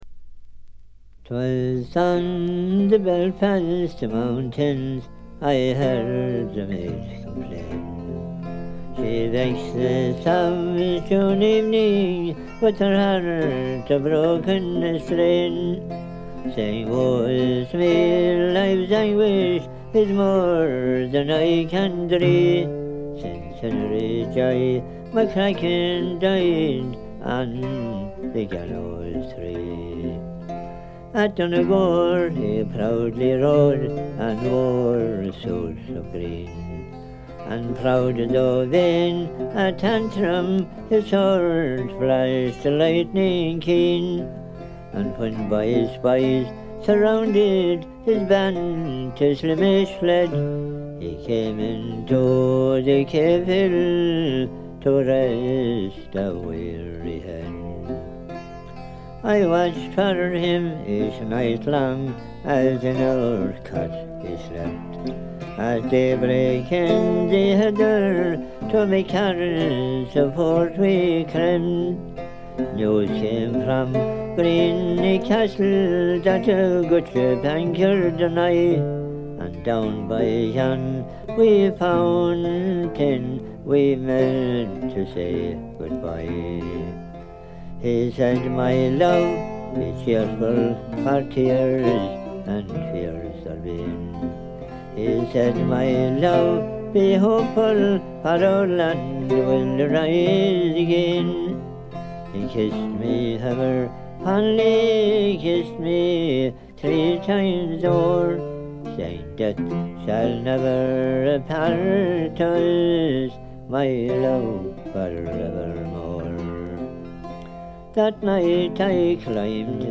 ごくわずかなノイズ感のみ。
売りである哀愁のアイリッシュムードもばっちり。
アイリッシュ・トラッド基本盤。
試聴曲は現品からの取り込み音源です。